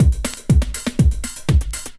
OSH Phaze 1 Beat 1_121.wav